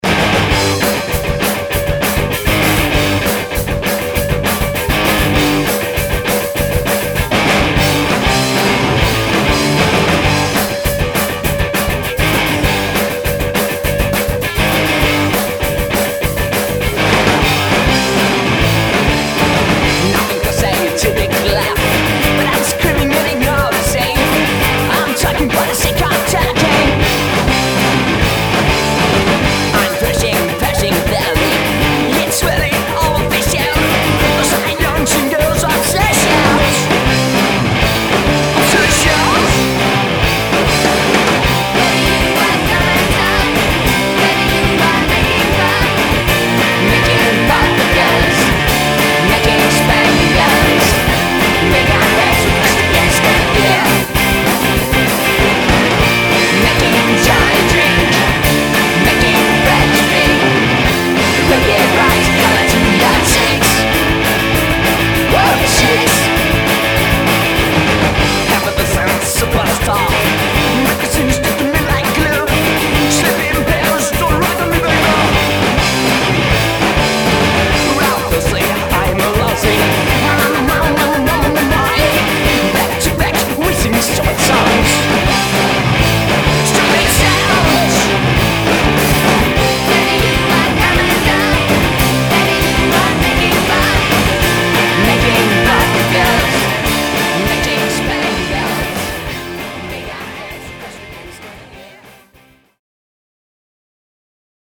Rock Bubble Gun mutant, survolté et plein de féminité